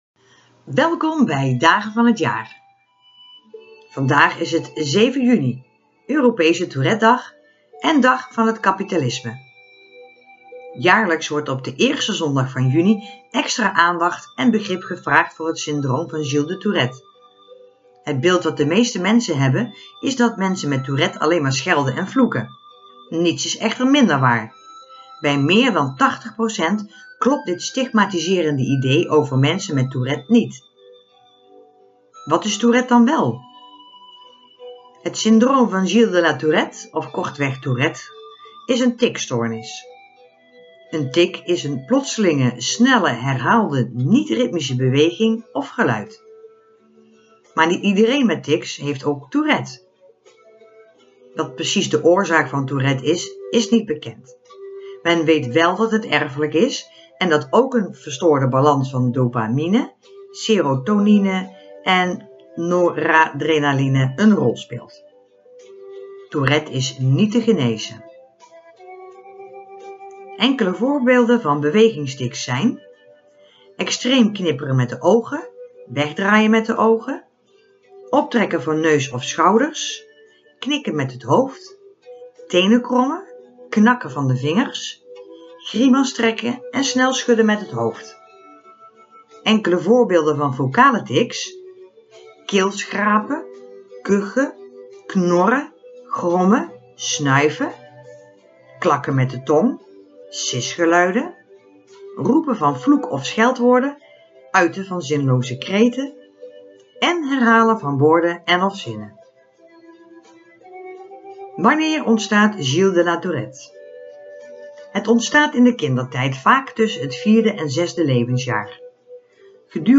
Keelschrapen, kuchen, knorren, grommen, snuiven
Elke beweging die je in deze video ziet is een motorische tic en elk geluid dat je hoort is een vocale tic!